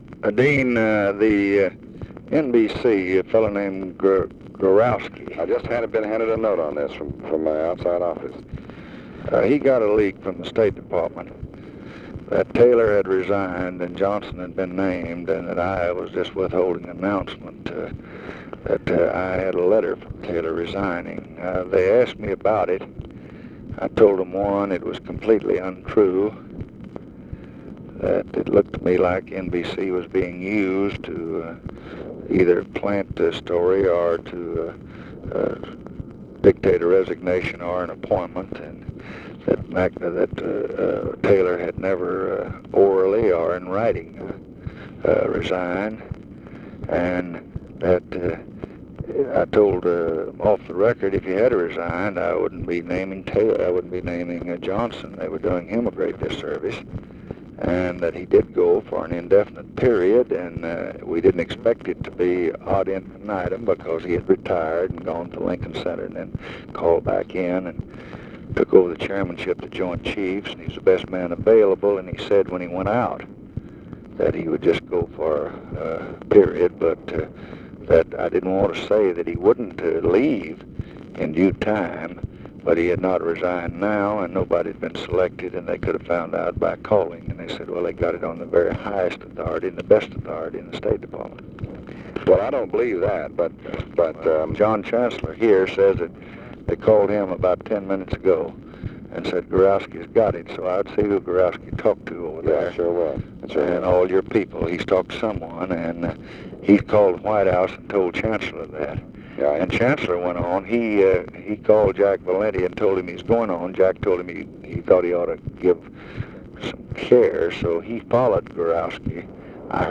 Conversation with DEAN RUSK, March 31, 1965
Secret White House Tapes